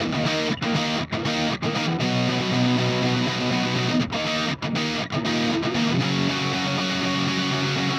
Power Pop Punk Guitar 02a.wav